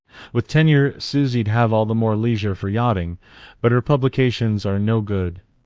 speech-style-transfer text-to-speech voice-cloning